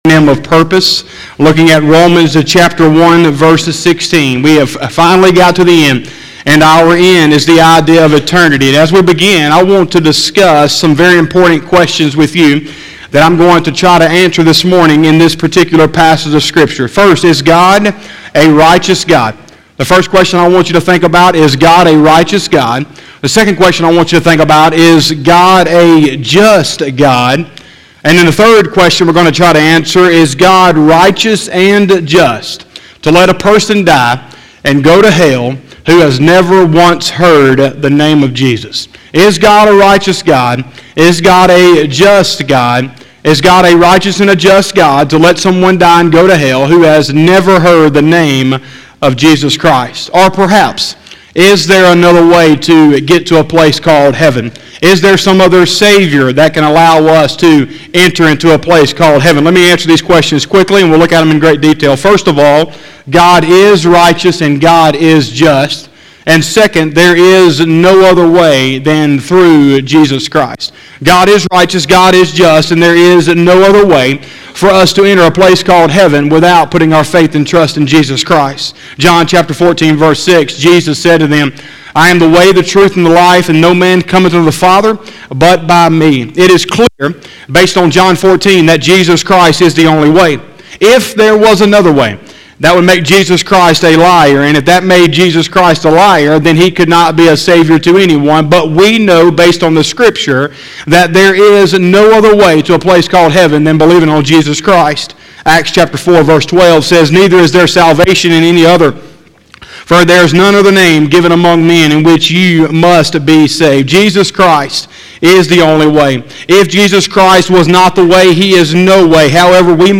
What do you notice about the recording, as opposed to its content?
11/15/2020 – Sunday Morning Service